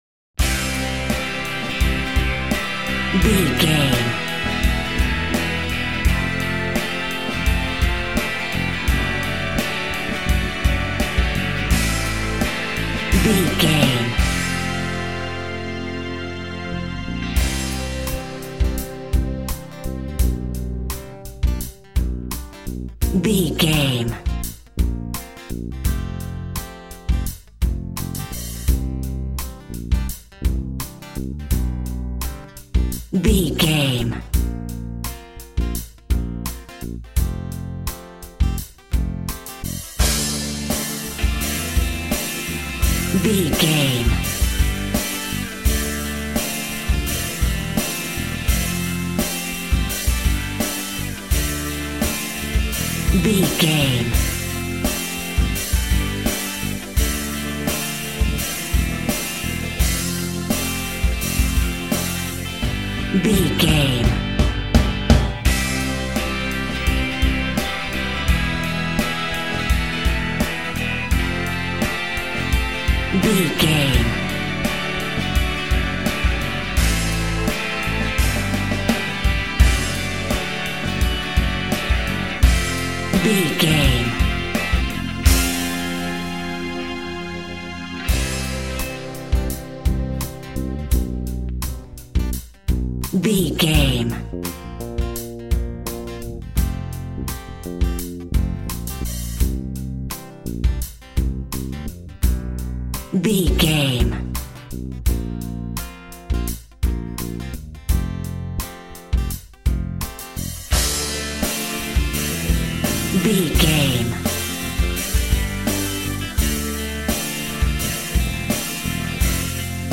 90s Brit Rock Pop.
Epic / Action
Fast paced
Ionian/Major
heavy rock
blues rock
distortion
pop rock music
drums
bass guitar
electric guitar
piano
hammond organ